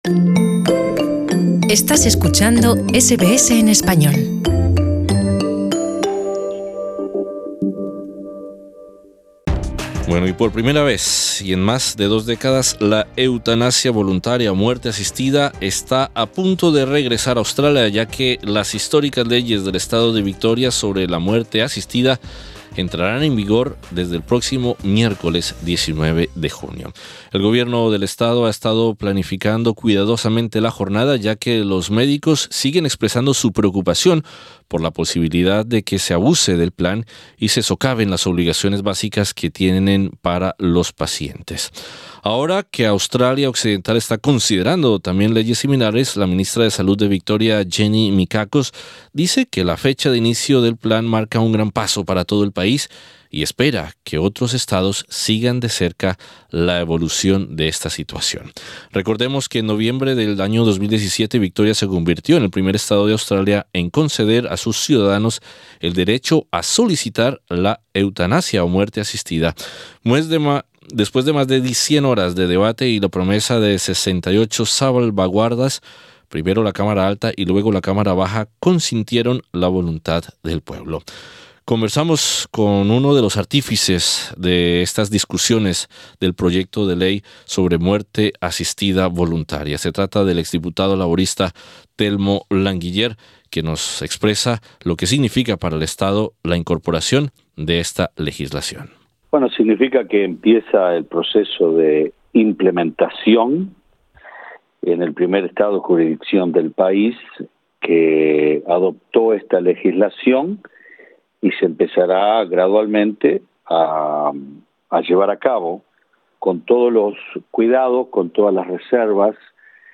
Conversamos con uno de los participantes de las discusiones sobre el proyecto de ley de muerte asistida, el ex diputado laborista Telmo Languiller.